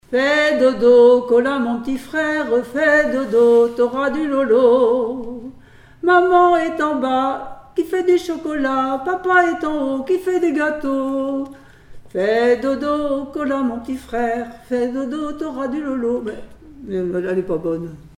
Mémoires et Patrimoines vivants - RaddO est une base de données d'archives iconographiques et sonores.
enfantine : berceuse
Comptines et formulettes enfantines
Pièce musicale inédite